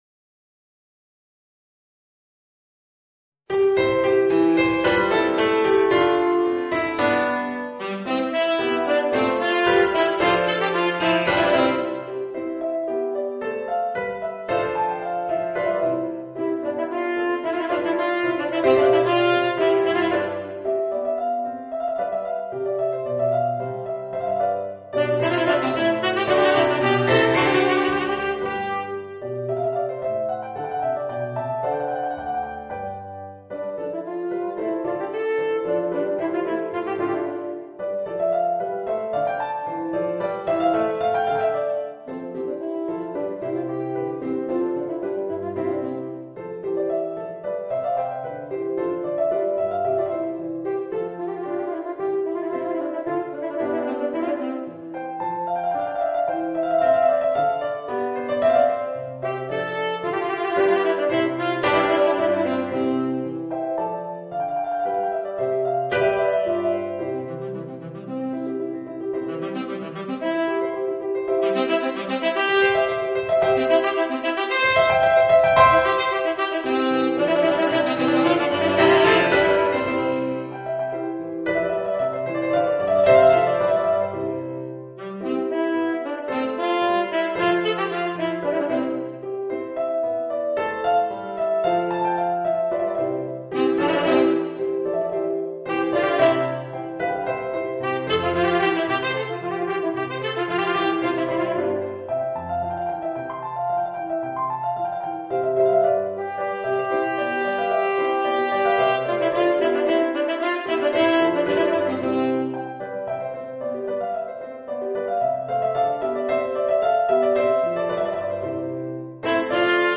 Voicing: Tenor Saxophone and Organ